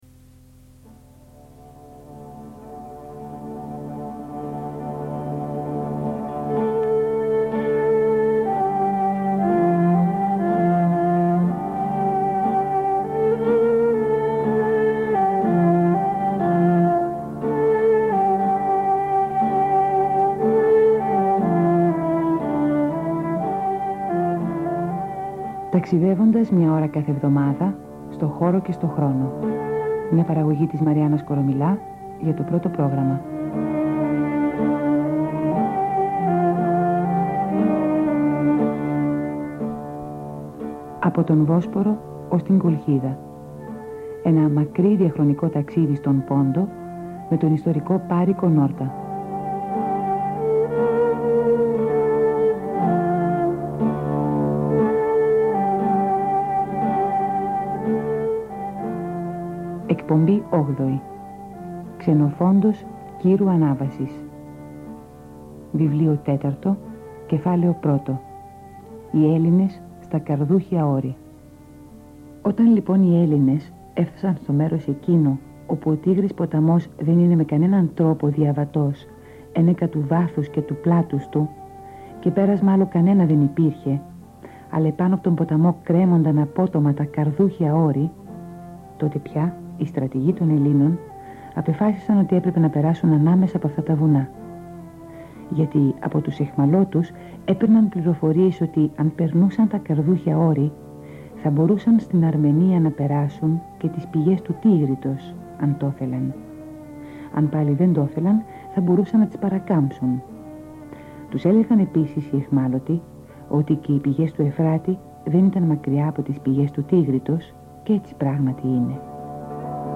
Υπότιτλος Ταξιδεύοντας στον χώρο και τον χρόνο Είδος Audio / Ακουστικό Χαρακτηρισμός Χαρακτηρισμός Ραδιοφωνική εκπομπή.